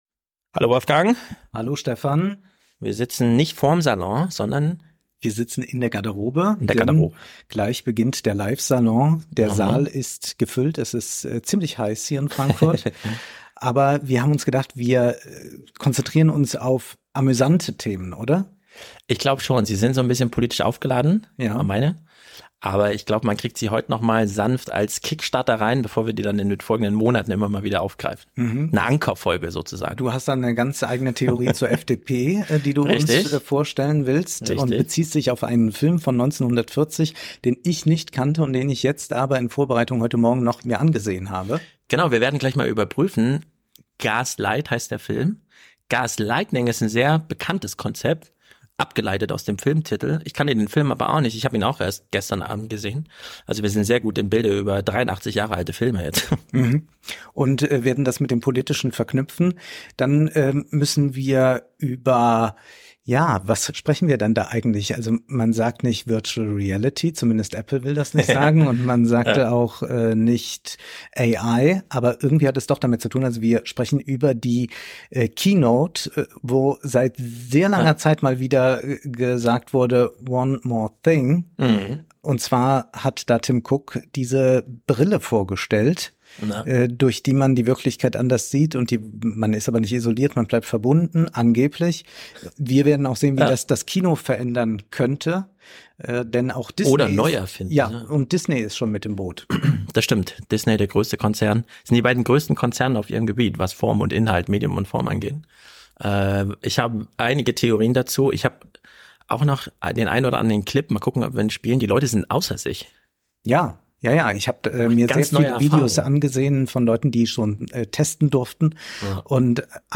sitzen in der Käs, noch hinter der Bühne.